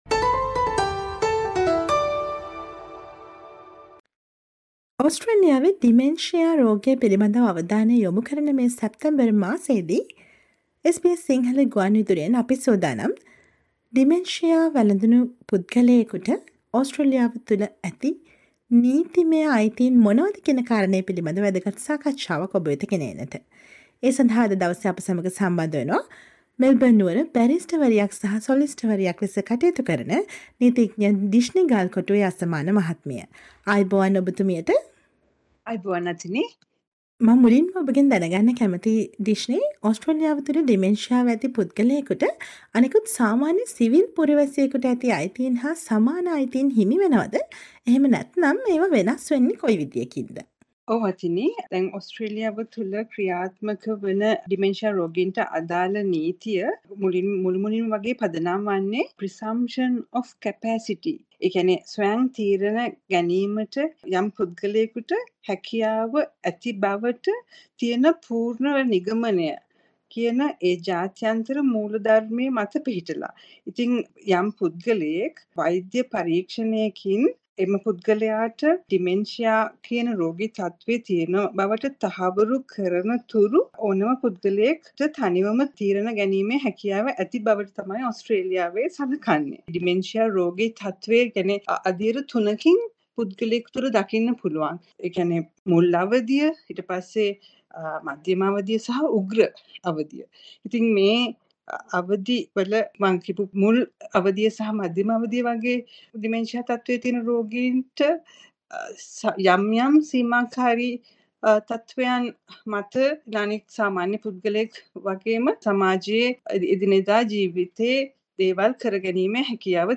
SBS Sinhala radio interview